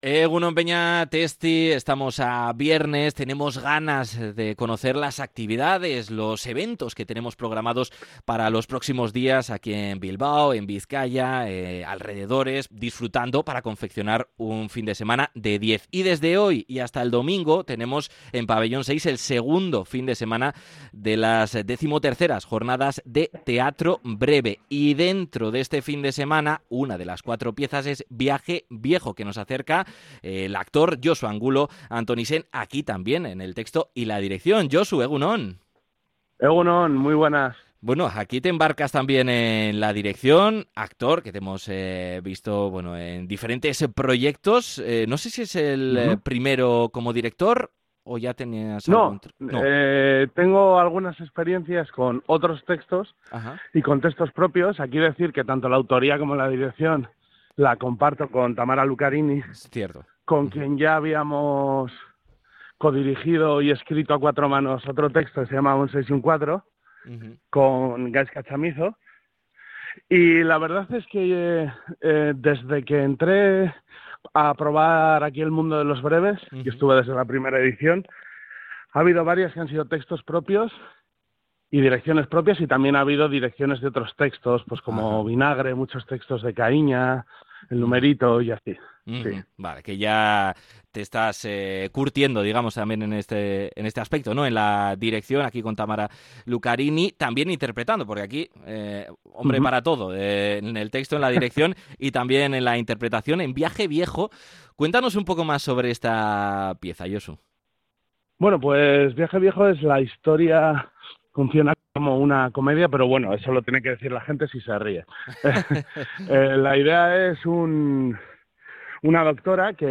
Durante la entrevista, subraya que el teatro breve ofrece precisamente esa posibilidad de probar funciones que no siempre coinciden con el perfil profesional habitual de cada participante.